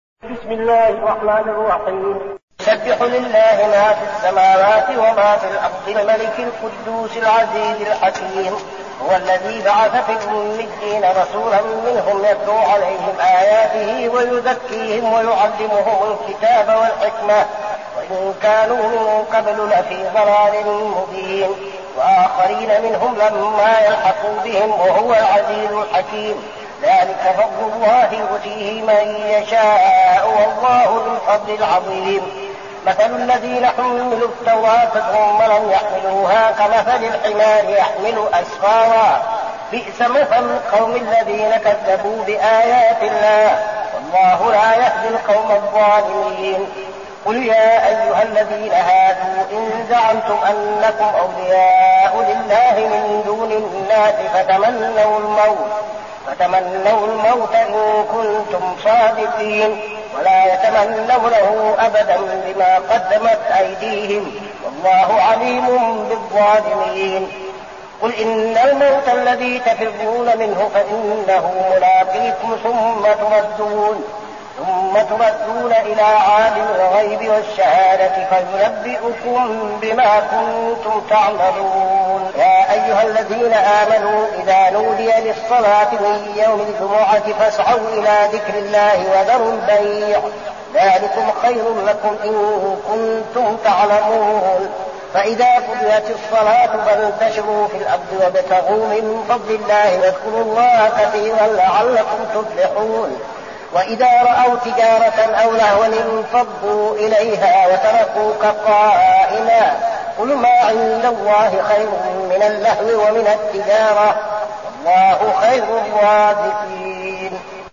المكان: المسجد النبوي الشيخ: فضيلة الشيخ عبدالعزيز بن صالح فضيلة الشيخ عبدالعزيز بن صالح الجمعة